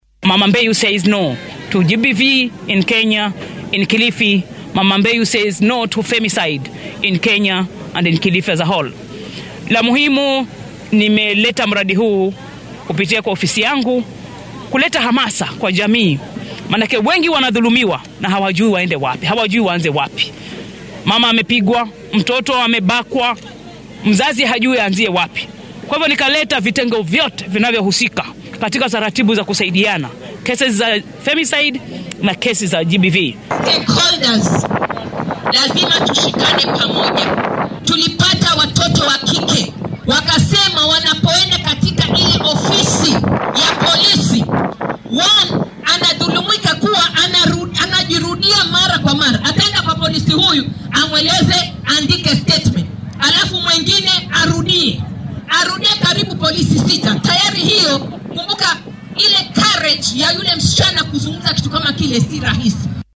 DHEGEYSO:Wakiilka haweenka Kilifi oo ka hadashay tacadiyada ka dhanka ah haweenka